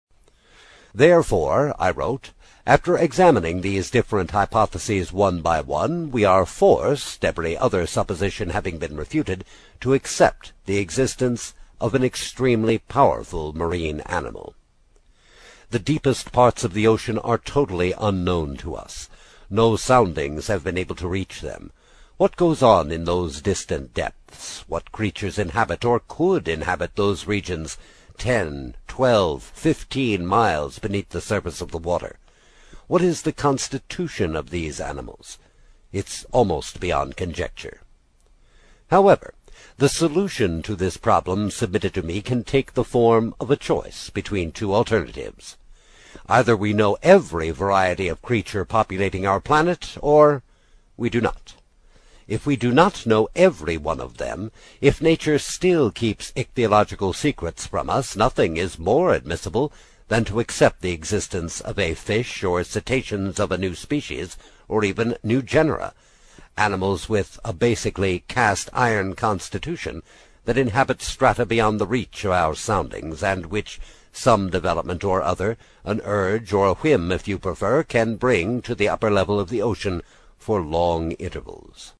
英语听书《海底两万里》第16期 第2章 正与反(5) 听力文件下载—在线英语听力室